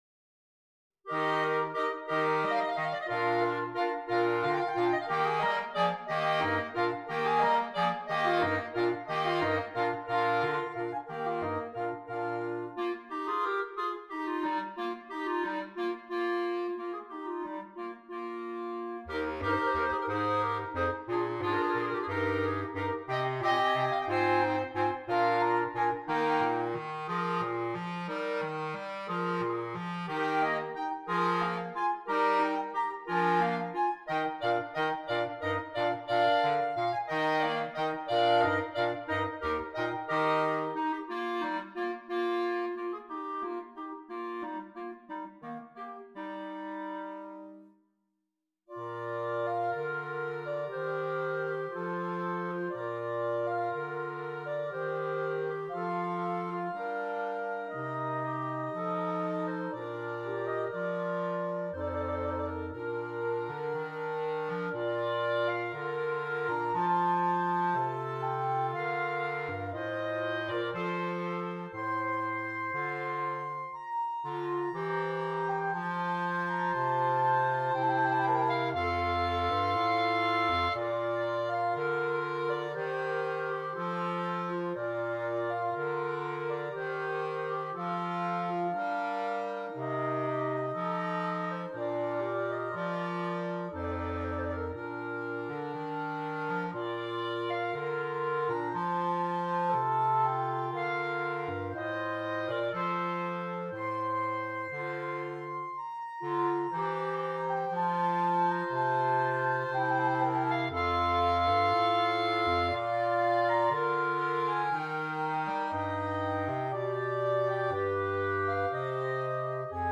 Voicing: Clarinet Quintet